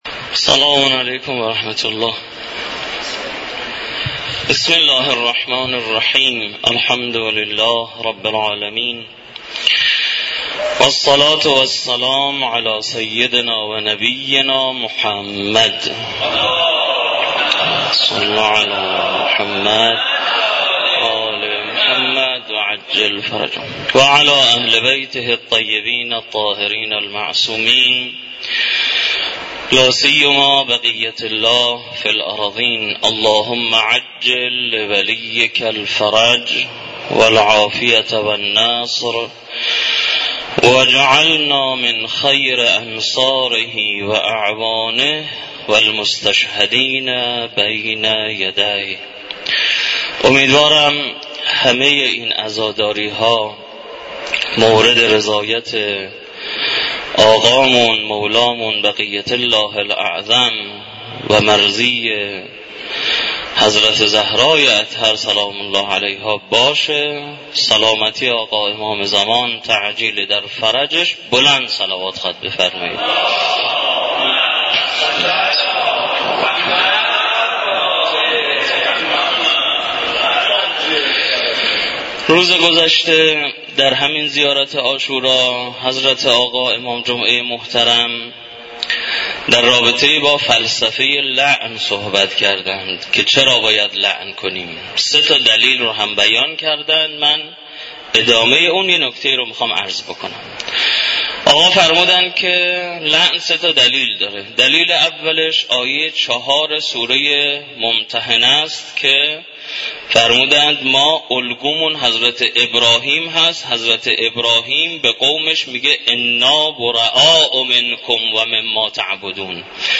مراسم عزاداری حضرت اباعبدالله الحسین علیه السلام همراه با قرائت زیارت عاشورا ، سخنرانی و مدّاحی در دانشگاه کاشان برگزار شد.